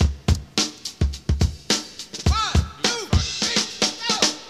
• 106 Bpm HQ Breakbeat Sample C# Key.wav
Free drum groove - kick tuned to the C# note. Loudest frequency: 2810Hz
106-bpm-hq-breakbeat-sample-c-sharp-key-QkZ.wav